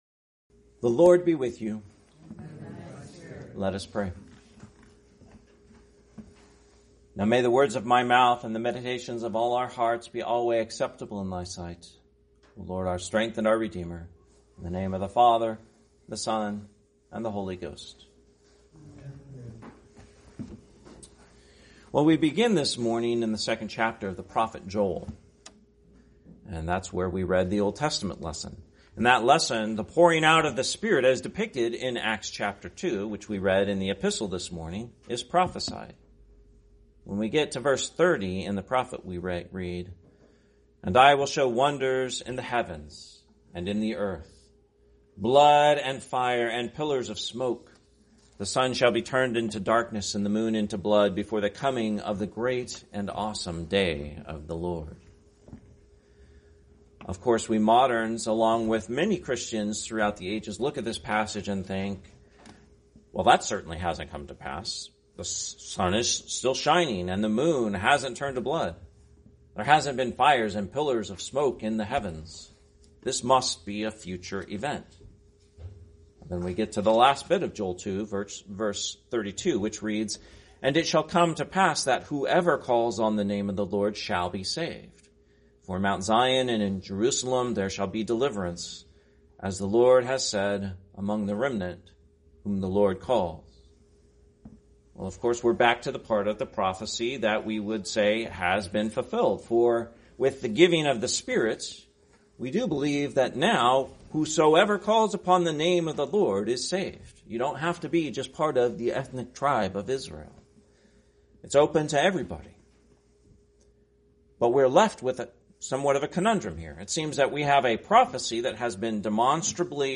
Sermon, Pentecost, 2025